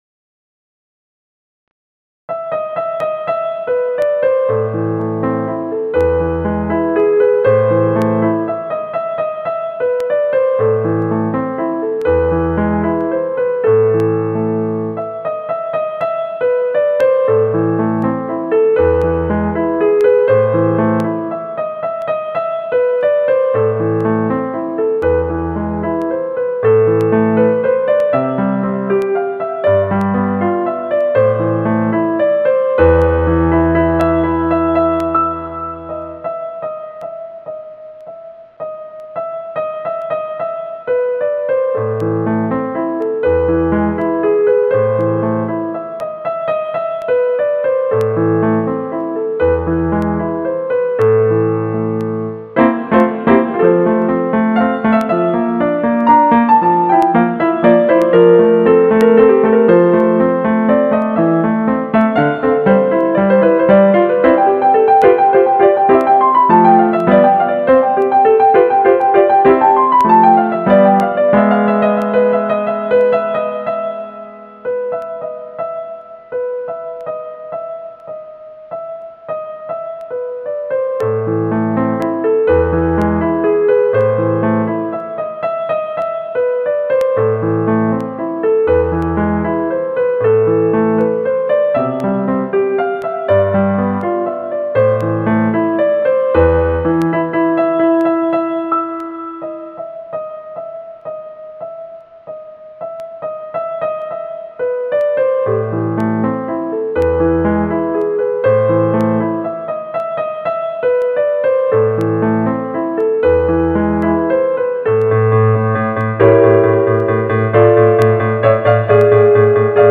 钢琴演奏：献给爱丽丝